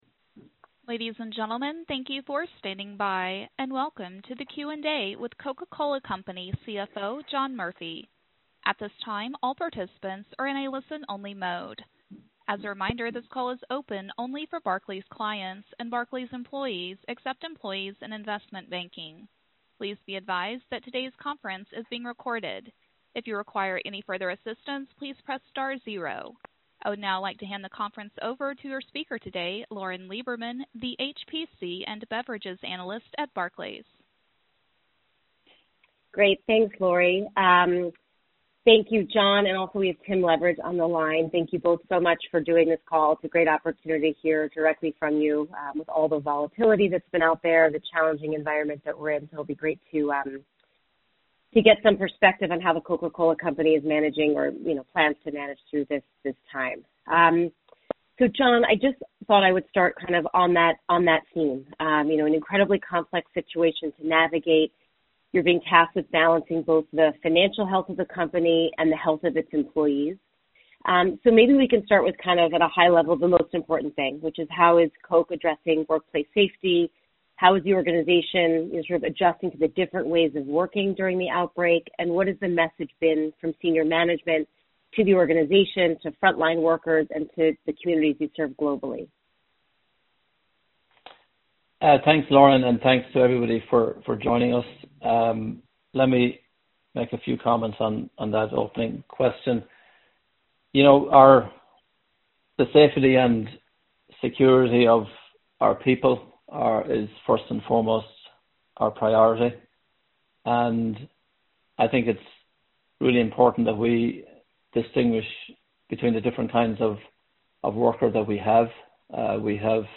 Barclays Conference Call